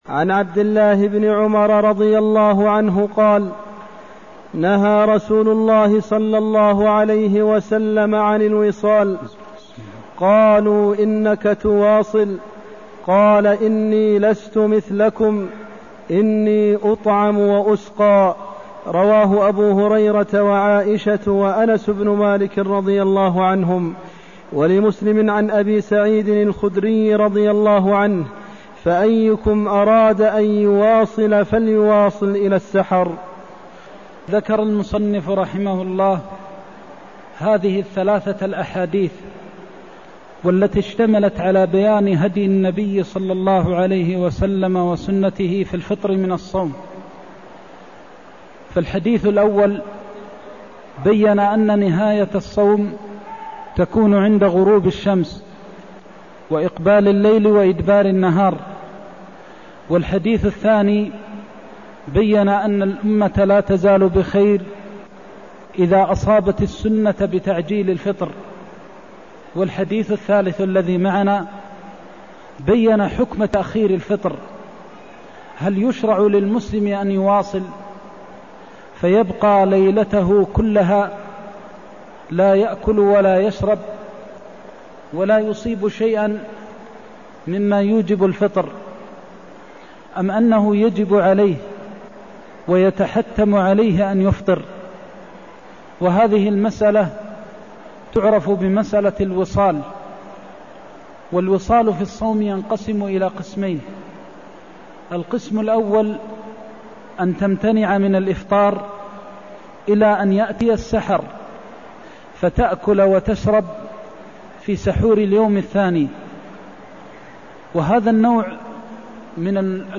المكان: المسجد النبوي الشيخ: فضيلة الشيخ د. محمد بن محمد المختار فضيلة الشيخ د. محمد بن محمد المختار النهي عن الوصال في الصوم (187)